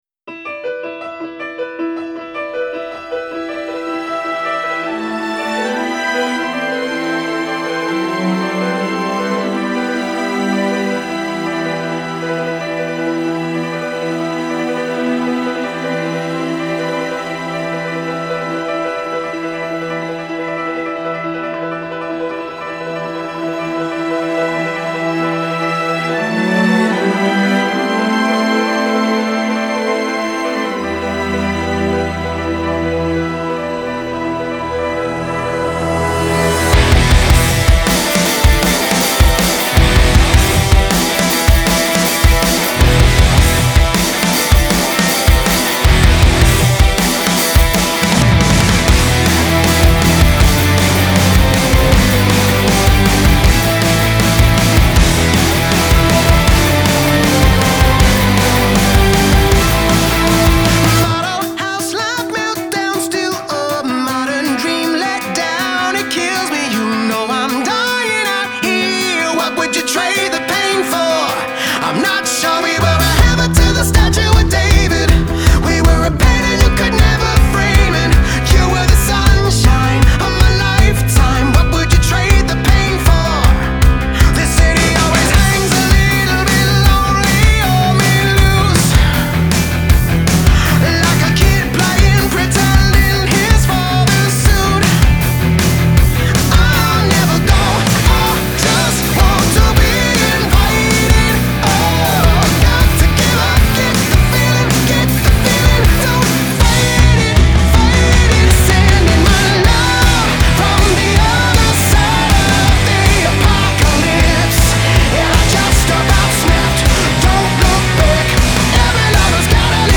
Genre : Pop, Rock